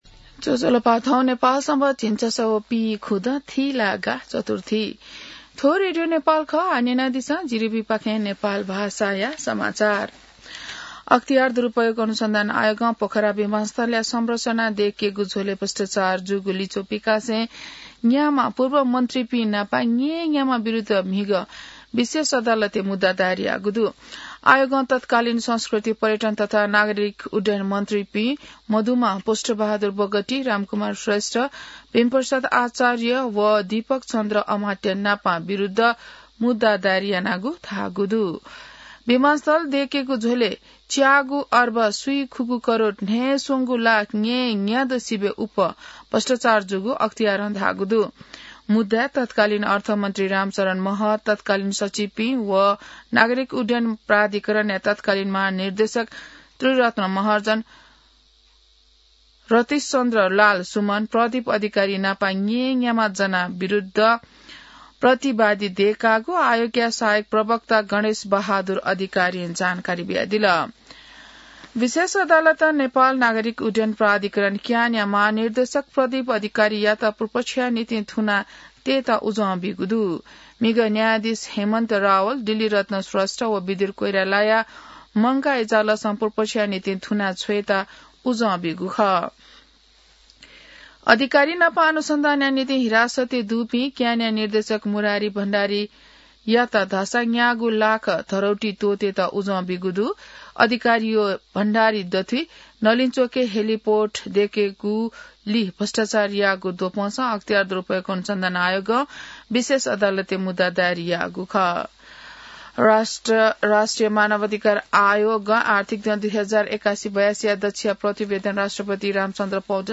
An online outlet of Nepal's national radio broadcaster
नेपाल भाषामा समाचार : २२ मंसिर , २०८२